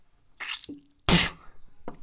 Escupitajo
Grabación sonora del sonido producido por una chica al escupir (tirar un escupitajo).
bucal
Sonidos: Acciones humanas